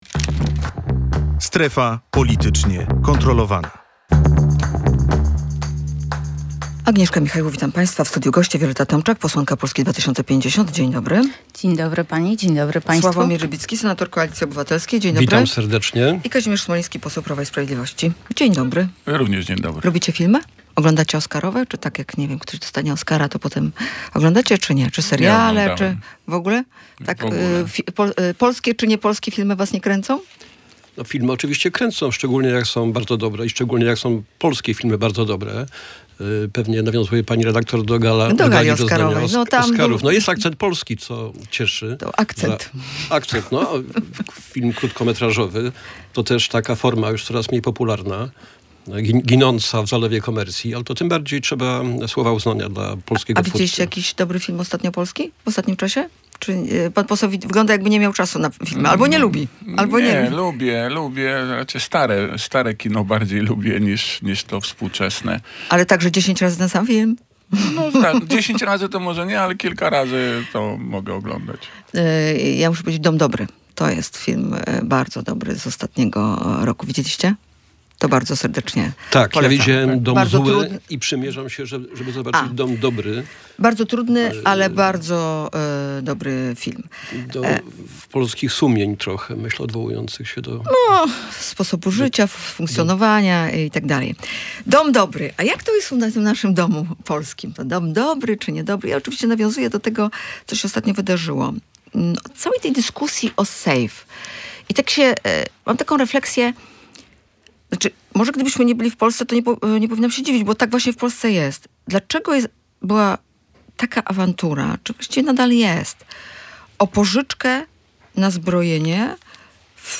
W dyskusji wzięli udział senator Koalicji Obywatelskiej Sławomir Rybicki, posłanka Polski 2050 Wioleta Tomczak oraz Kazimierz Smoliński, poseł Prawa i Sprawiedliwości.